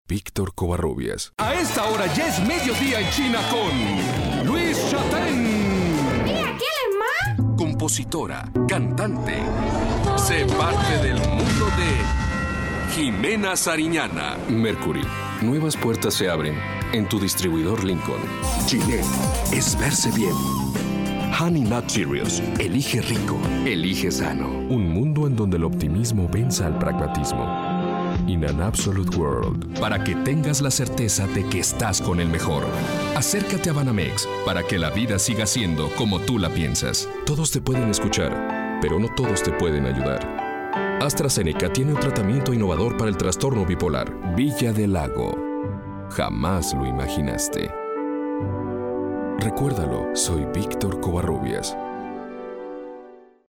Locutor y actor de doblaje
Sprechprobe: Werbung (Muttersprache):